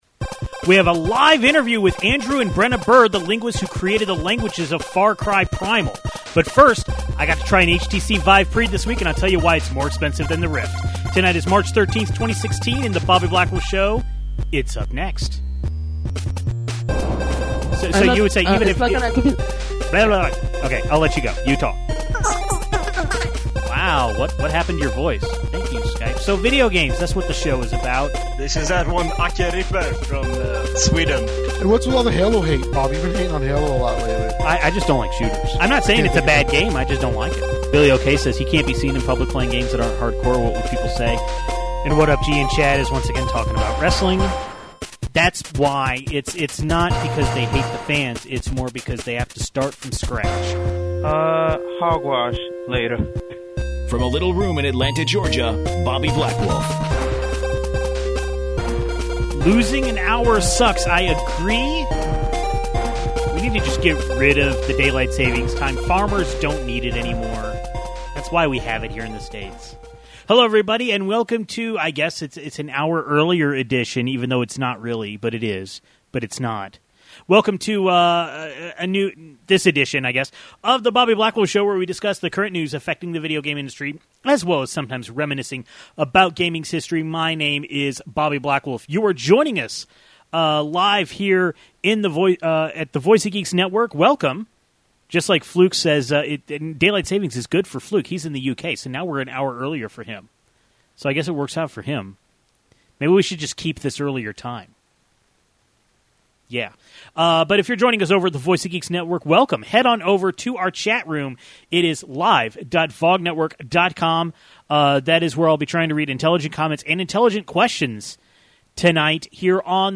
This interview beings 24 minutes into the podcast. But first, I got to try the HTC Vive Pre and I talk about why it's probably going to be the best VR hardware to come out in 2016, and why it's the most expensive.